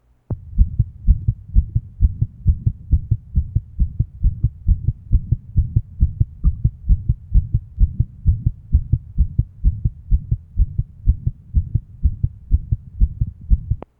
Date 1970 Type Systolic and Diastolic Abnormality Innocent murmur Straight back syndrome. Ejection murmur and on occasion early diastolic murmur at 2L (the latter not recorded well).